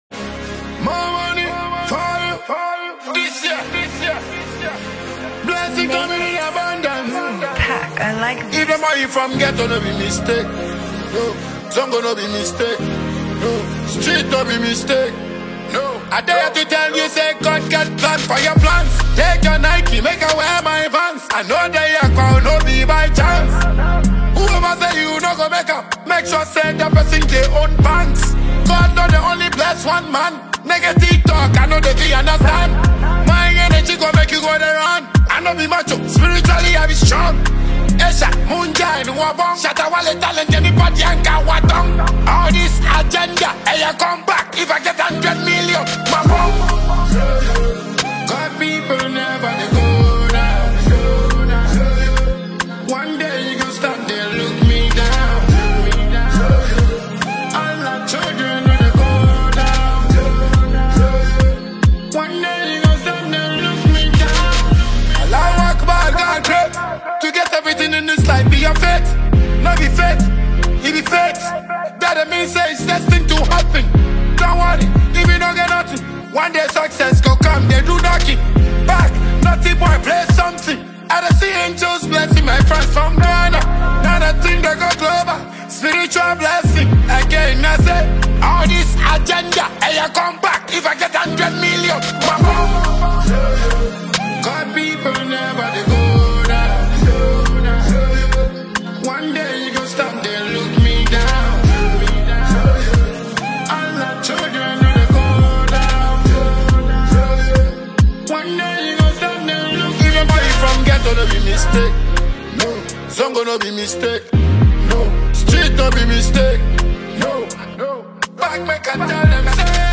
returns with a soulful yet gritty anthem
is a mid-tempo Dancehall-Highlife fusion